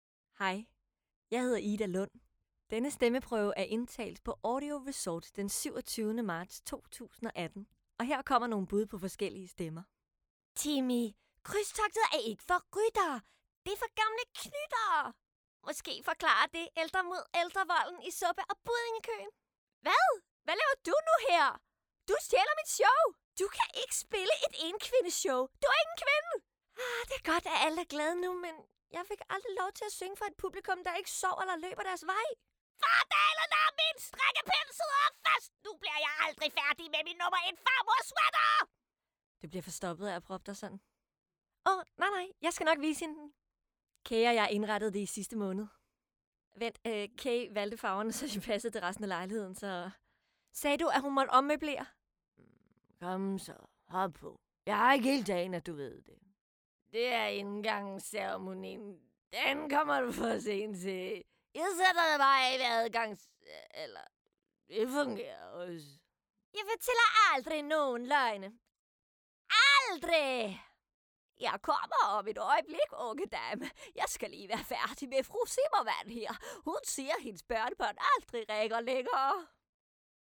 SPEAK OG SANG
STEMMEPRØVER
Som skuespiller lever jeg mig nemt ind i rollen og har flere stemmetyper og kvaliteter at byde på.